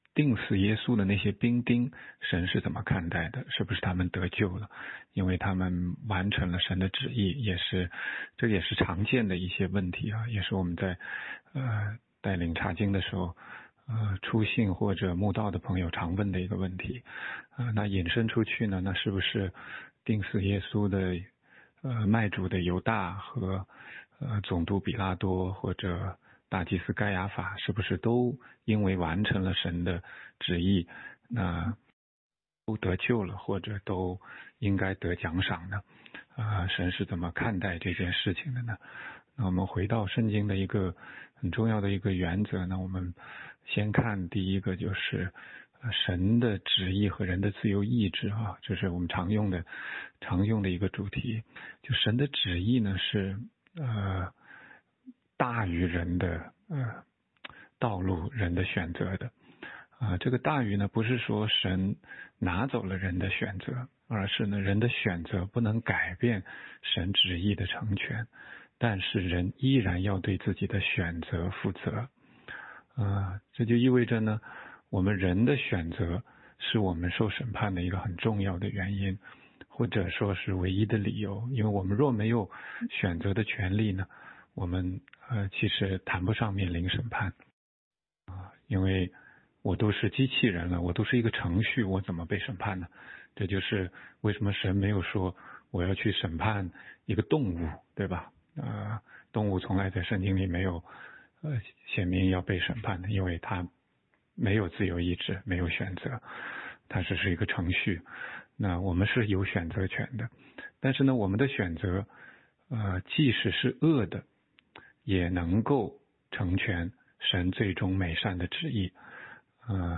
16街讲道录音 - 钉死耶稣的那些兵丁，神是怎么看待的？是不是他们得救了？因为他们完成了神的旨意。